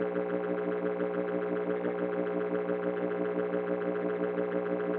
Claro, que eu estava com a guitarra desplugada, mas aprecie o ruído girando na sua orelha.